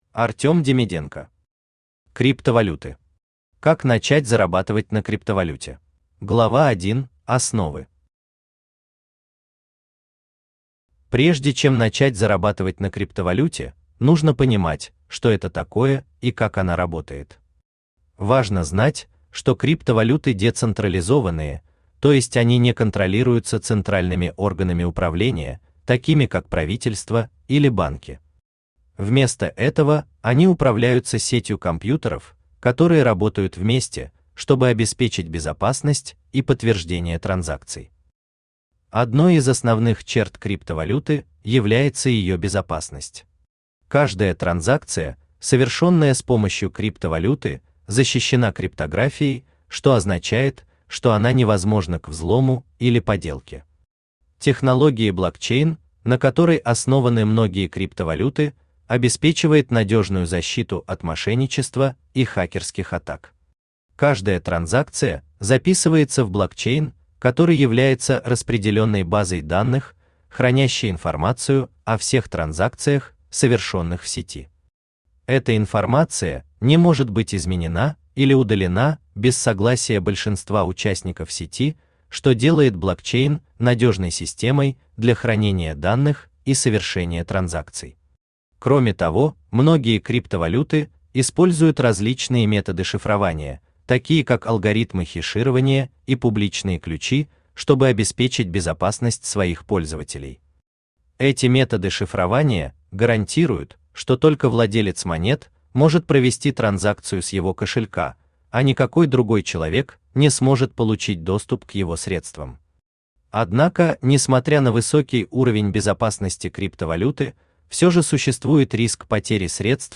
Автор Артем Демиденко Читает аудиокнигу Авточтец ЛитРес.